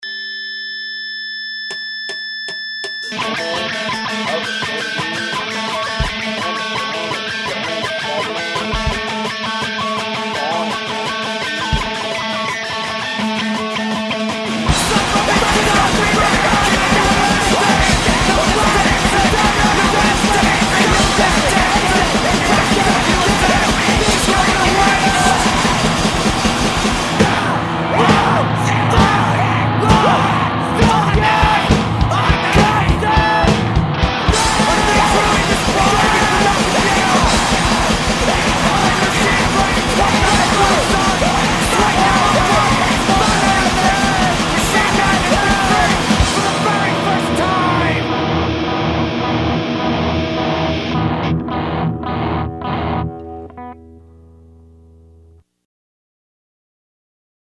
Left unmastered because we're beautiful